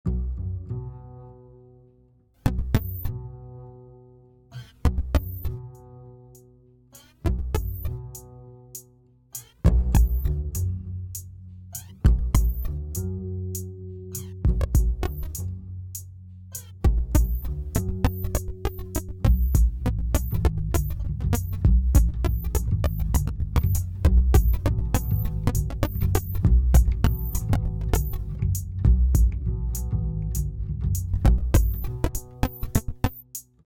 INSTRUCTIONS: Create an audio file which sounds like you, without speaking.
this is a combination of me breathing and lightly snapping my fingers in time to my heartbeat... then sped up to about 100 bpm and tweaked. oh, and i threw in some drums and a bass or two just cause i can.